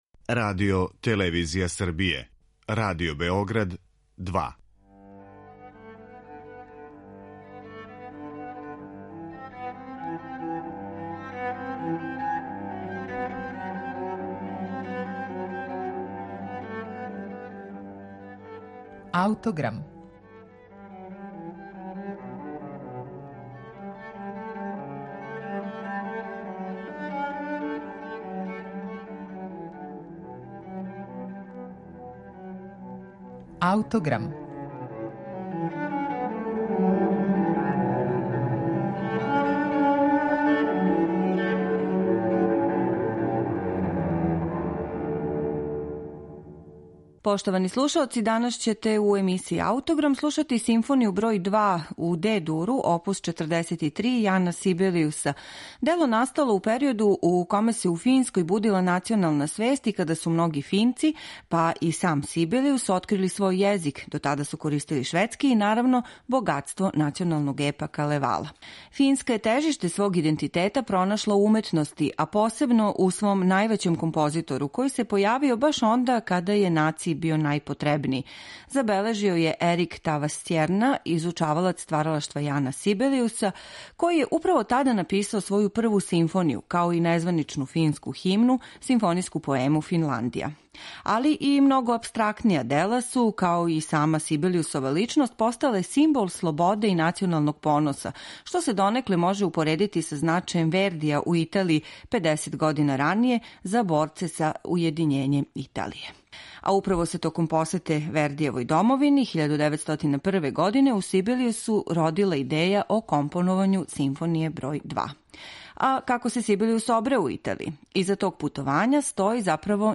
‒ писао је највећи фински композитор Јан Сибелијус 1901. године из Италије, када је започео компоновање своје Симфоније бр. 2 у Де-дуру оп. 43.
Ово дело, које је 1902. године први пут извело Филхармонијско друштво из Хелсинкија под управом самог композитора, слушаћете данас у емисији Аутограм у интерпретацији Бечке филхармоније којом диригује Еса-Пека Салонен.